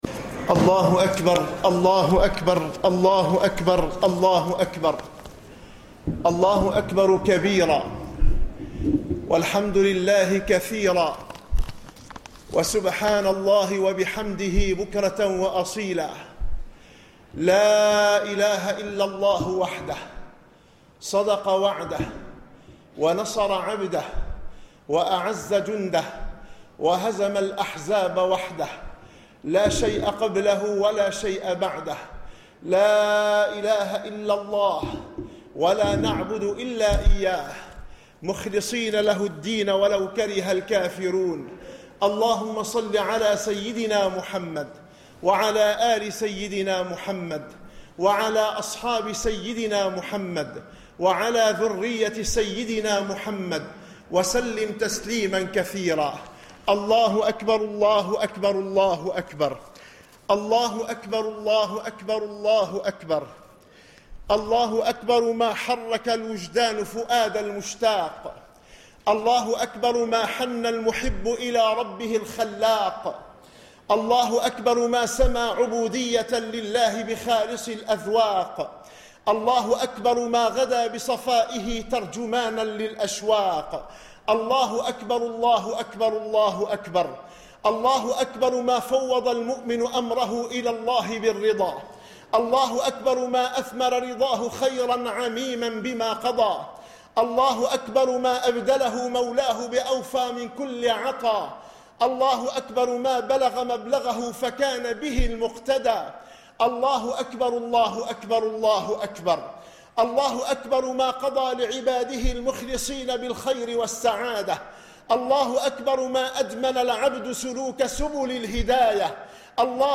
خطبة عيد الأضحى